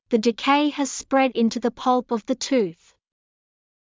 ｻﾞ ﾃﾞｨｹｲ ﾊｽﾞ ｽﾌﾟﾚｯﾄﾞ ｲﾝﾄｩ ｻﾞ ﾊﾟﾙﾌﾟ ｵﾌﾞ ｻﾞ ﾄｩｰｽ